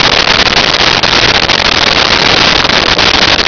Sfx Surface Gravel Loop
sfx_surface_gravel_loop.wav